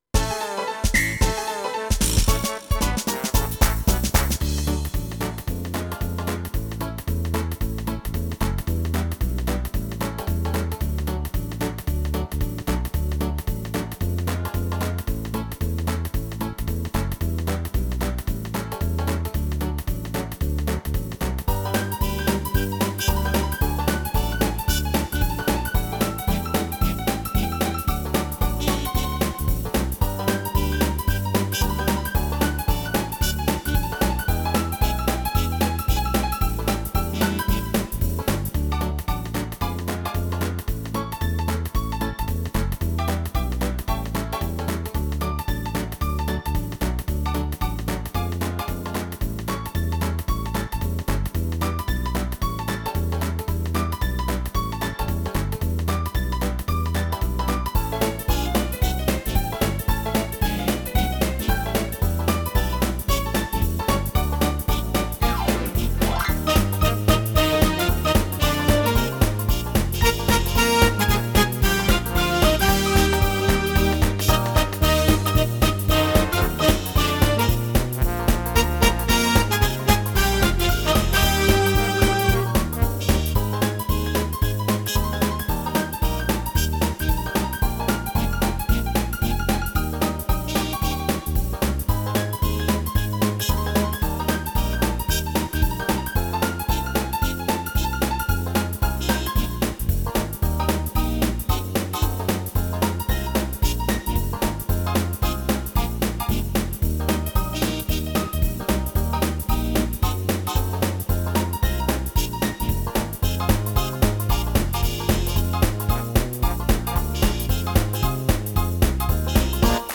• Жанр: Детские песни
Слушать Минус